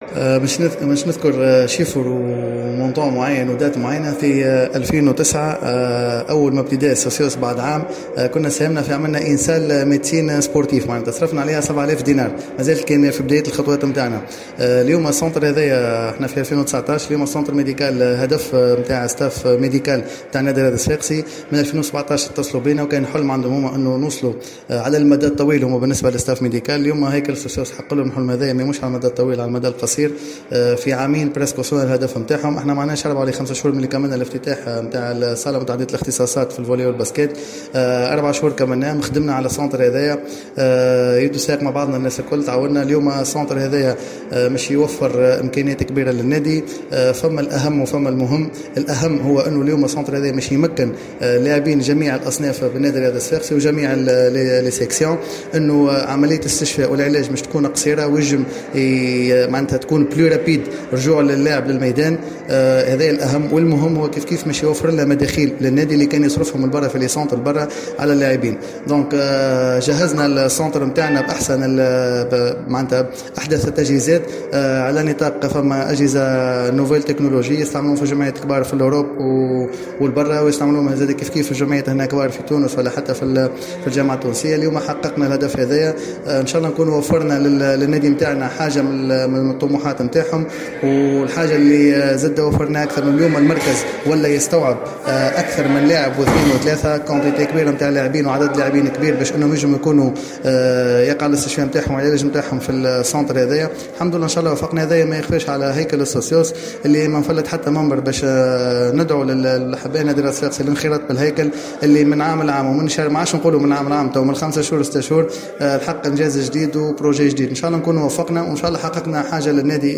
تصريح لجوهرة اف ام